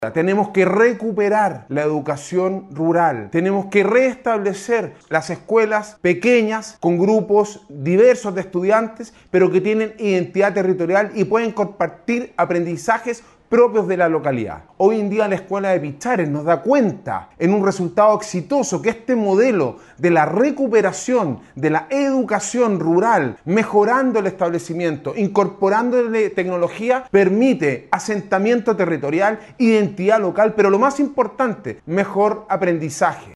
Alcalde-Sebastian-Alvarez-valora-la-educacion-rural-.mp3